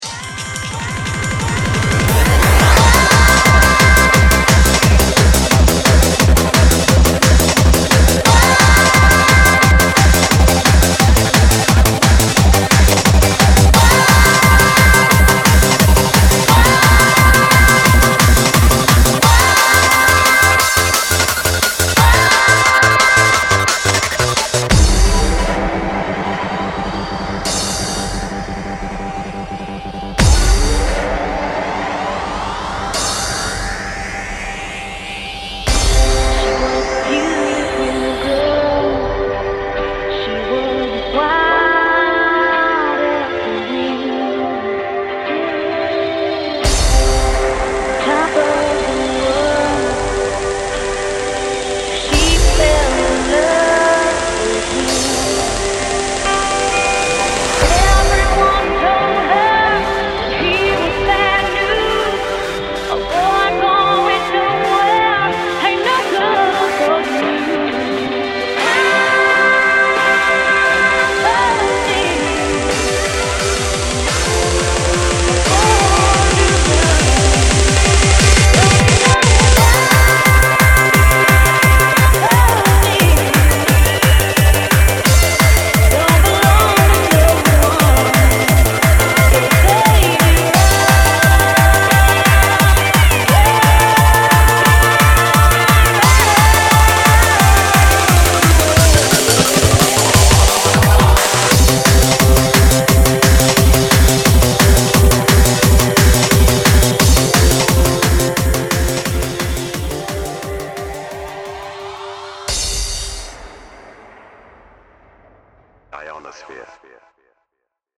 Oh, I always think of her as a Hardcore vocalist -